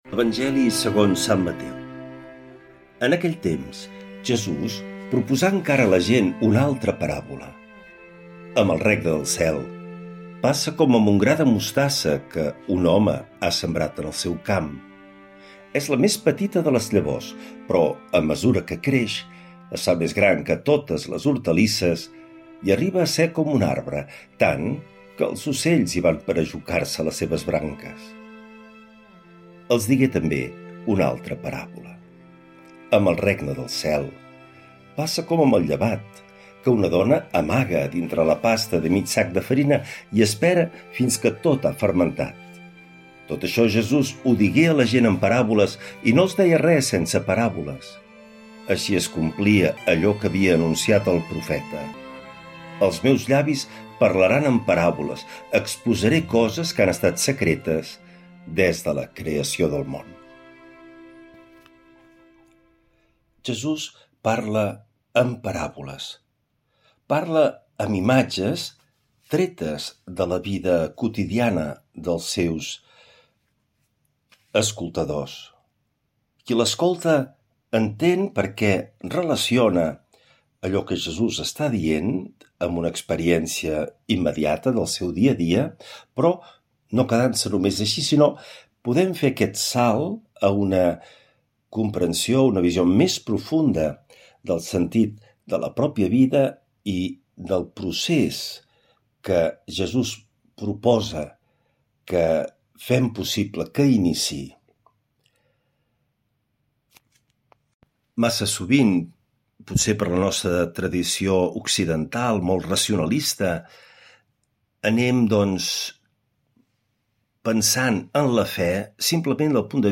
L’Evangeli i el comentari de dilluns 28 de juliol del 2025.
Lectura de l’evangeli segons sant Mateu